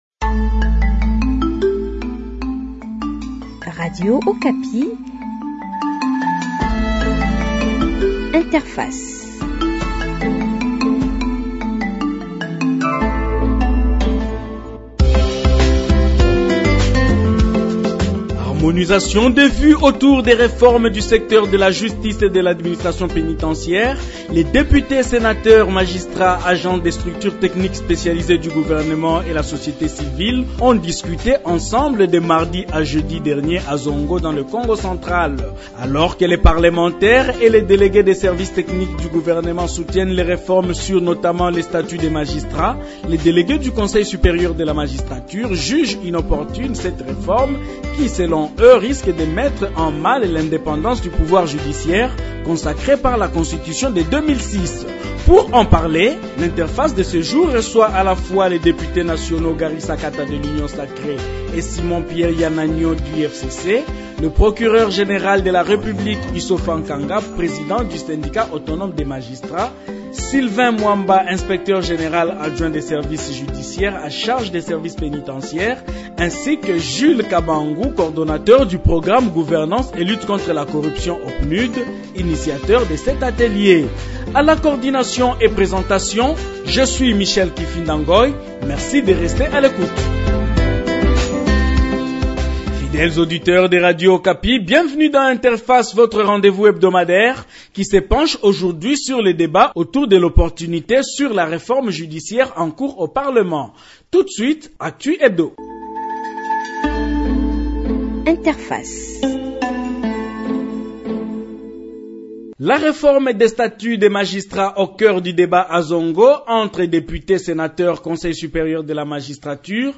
Pour en parler, Interface de ce jour reçoit à la fois les députés nationaux Gary Sakata de l’Union Sacrée et Simon Pierre Iyananion du FCC, le procureur général de la République Isako Nkanga